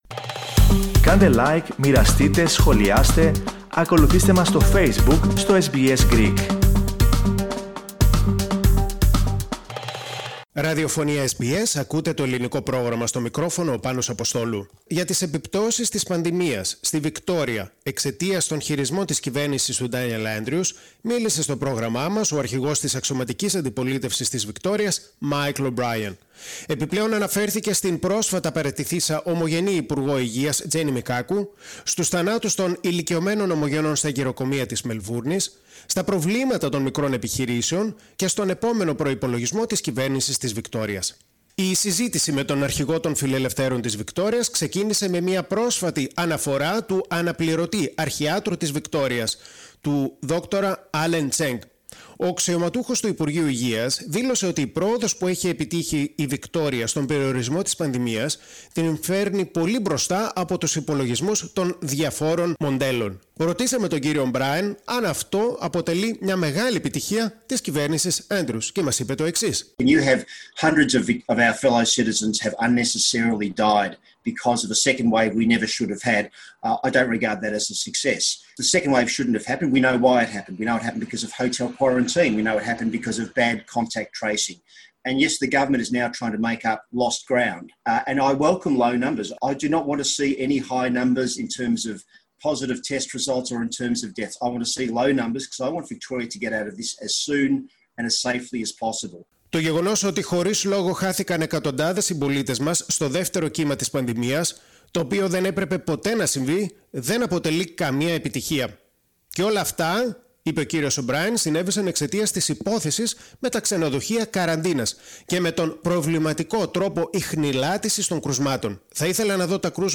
Στις «καταστροφικές επιπτώσεις» απ’ τους χειρισμούς της Κυβέρνησης του Ντάνιελ Άντριους στην αντιμετώπιση της πανδημίας αναφέρθηκε σε συνέντευξή του στο SBS Greek, ο αρχηγός της Αξιωματικής Αντιπολίτευσης της Βικτώριας, Μάικλ Ο’Μπράιεν. Μίλησε για την πρώην υπουργό Υγείας, Τζένη Μικάκου, για τους θανάτους των ηλικιωμένων ομογενών στα γηροκομεία της Μελβούρνης, για την οικονομία και το επόμενο πολιτειακό προϋπολογισμό.
michael_obrien_-_synenteyxi_sta_ellinika_-_sbs_greek.mp3